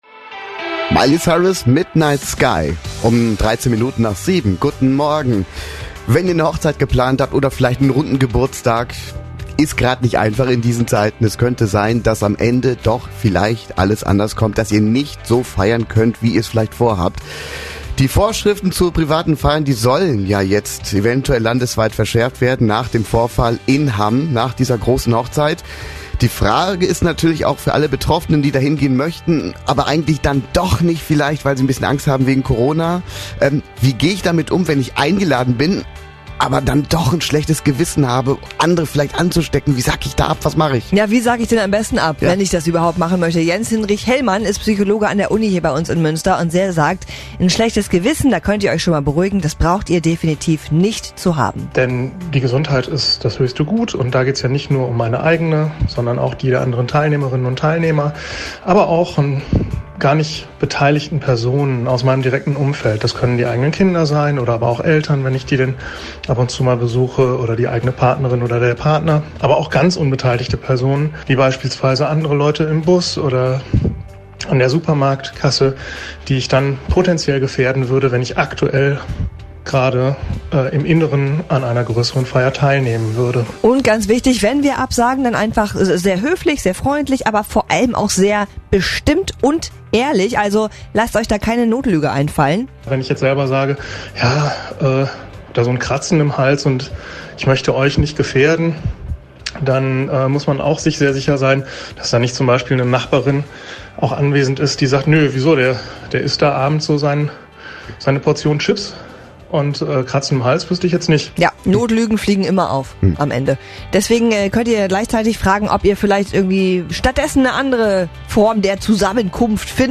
mitschnitt_psychologe.mp3